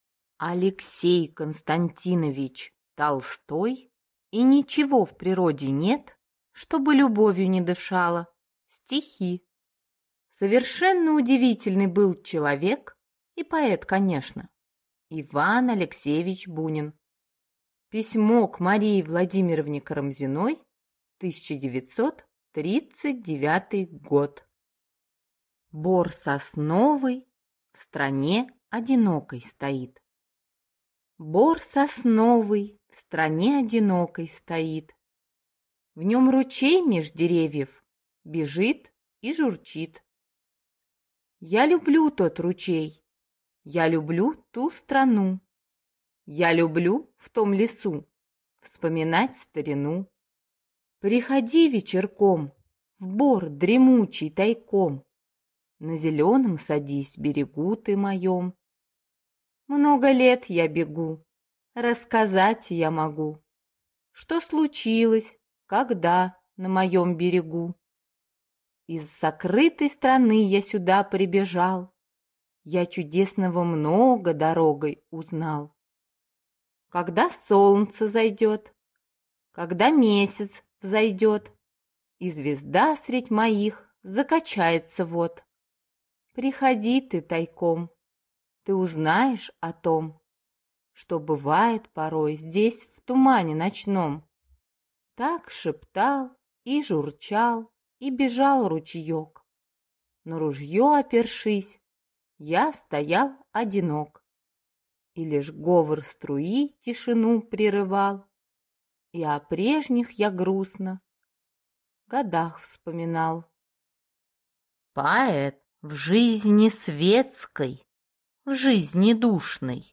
Аудиокнига И ничего в природе нет, что бы любовью не дышало (сборник) | Библиотека аудиокниг